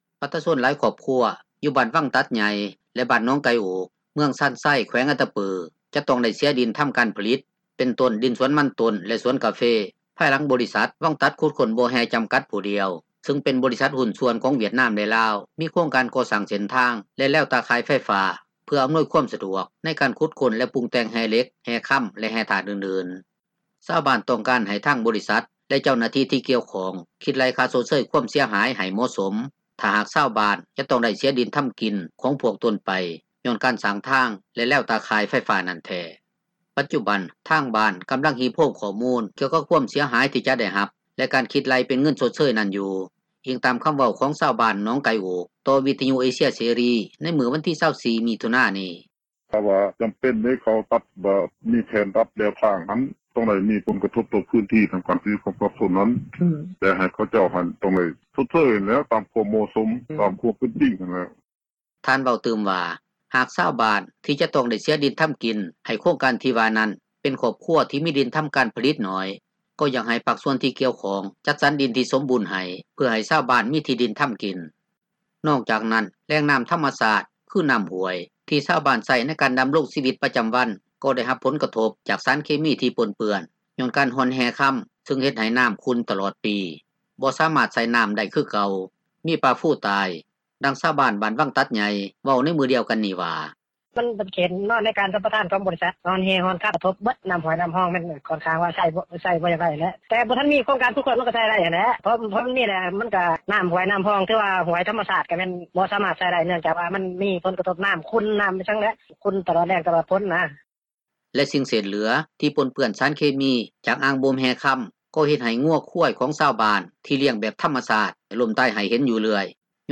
ດັ່ງຊາວບ້ານບ້ານວັງຕັດໃຫຍ່ ເວົ້າໃນມື້ດຽວກັນນີ້ວ່າ:
ດັ່ງຊາວບ້ານໜອງໄກ່ໂອກ ອີກຜູ້ນຶ່ງ ກໍເວົ້າວ່າ: